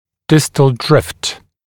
[‘dɪst(ə)l drɪft][‘дист(э)л дрифт]самороизвольное смещение зубов в дистальном направлении, дистальный дрейф